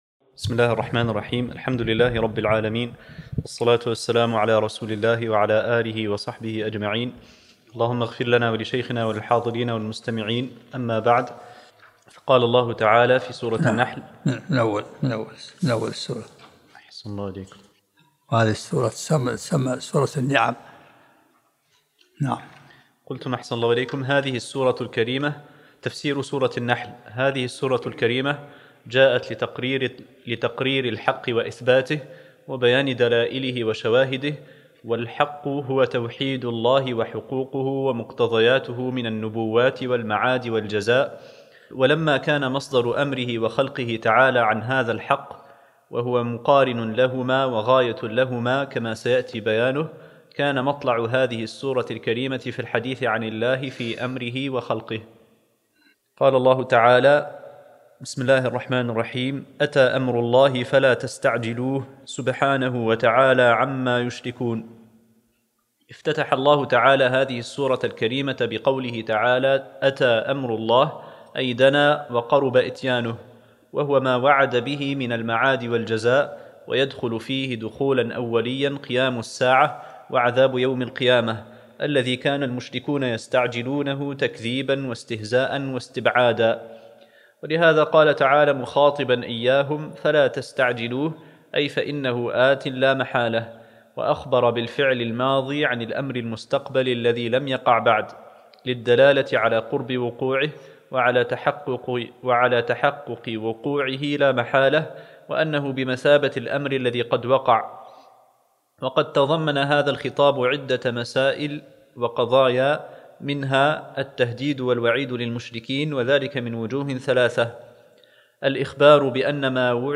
الدرس الأول من سورة النحل 2